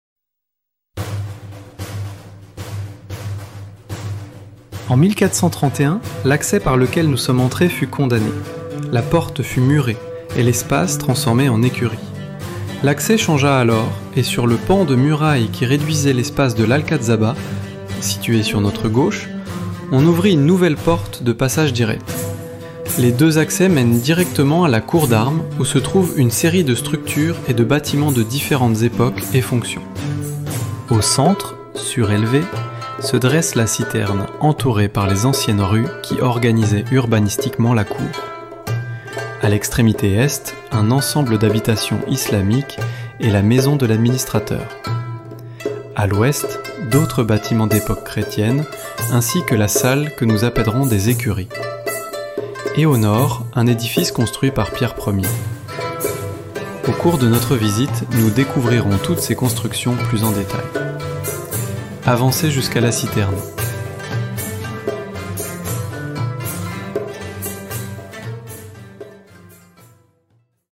Ruta audioguiada
audioguia-frances-qr4.mp3